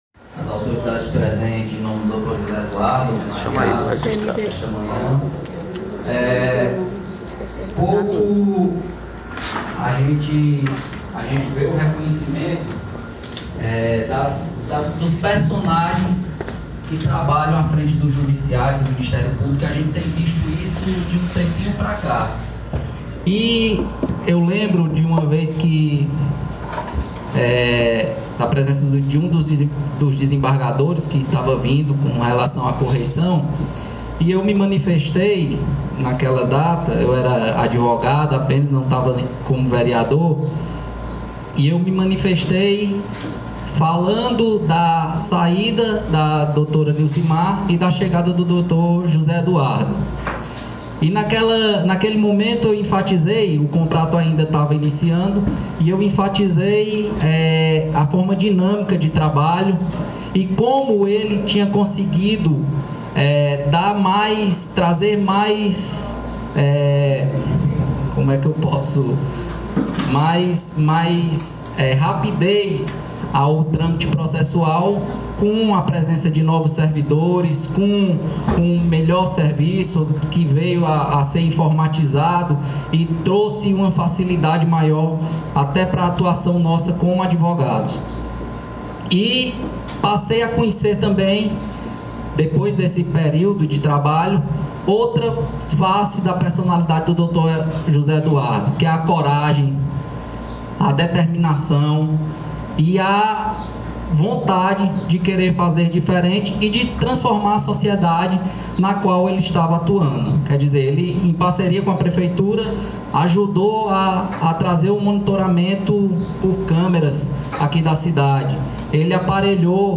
áudio da solene de entrega de título de cidadão do dia 13.02.2020